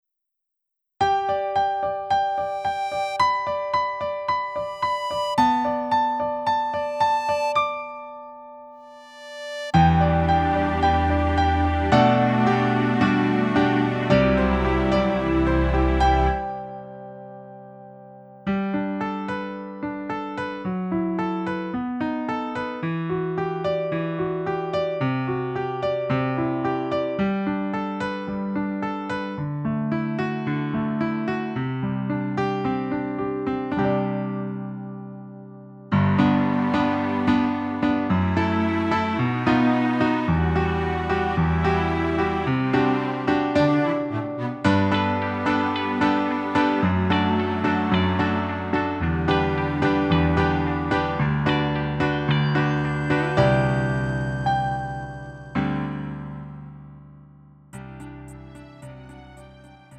음정 -1키 4:09
장르 가요 구분 Lite MR